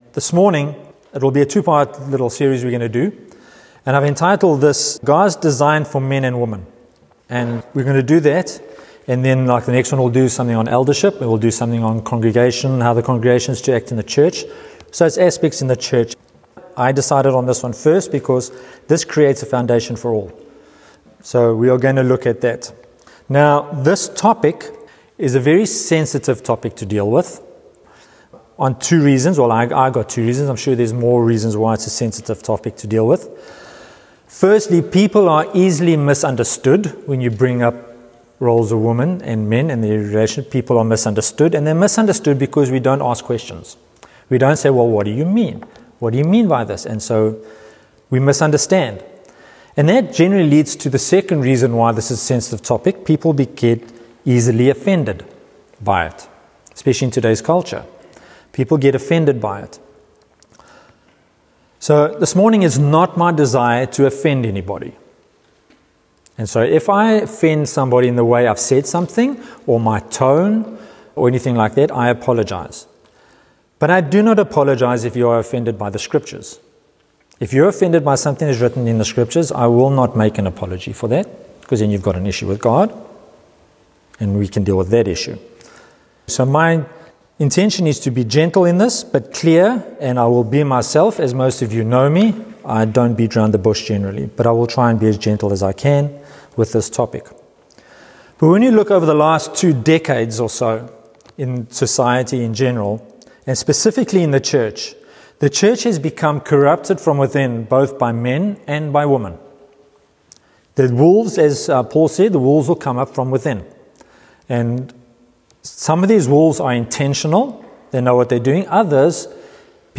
This sermon addresses a sensitive and often misunderstood topic by returning to Scripture as our final authority.